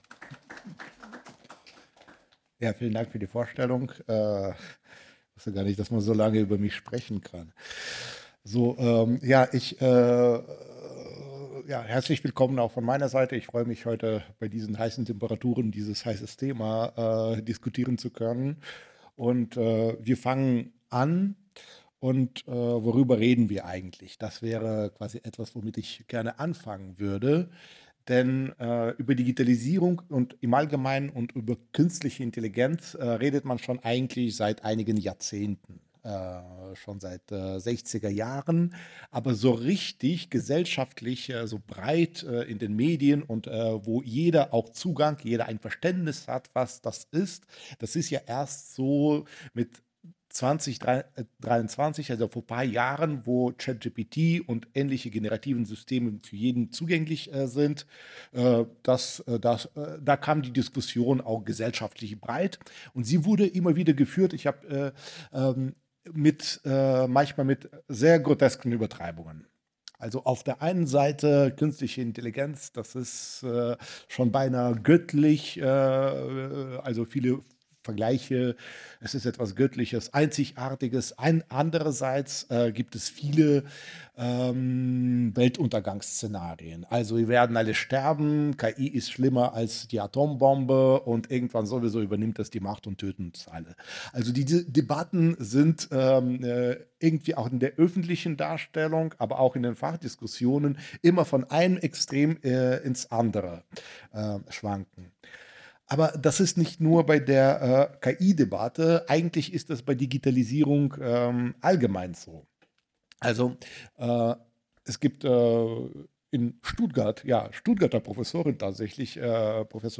Vortrag